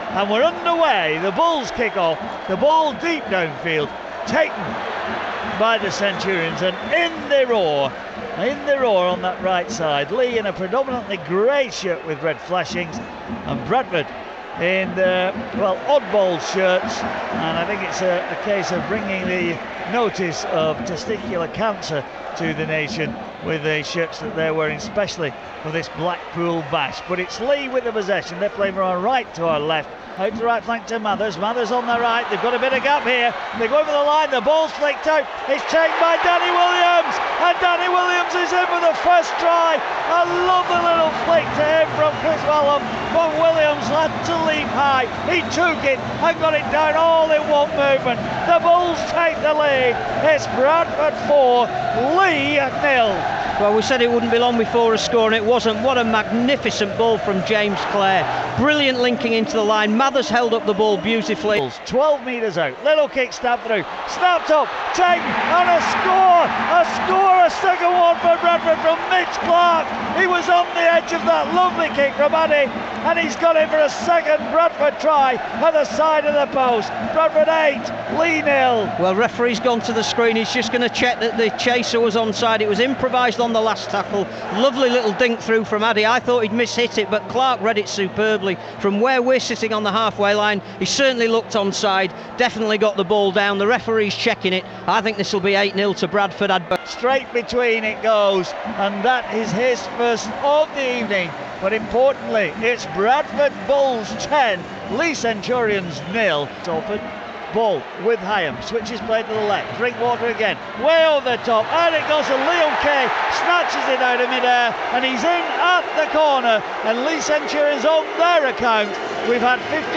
Highlights of the Summer Bash clash between Bradford Bulls and Leigh Centurions in the Blackpool.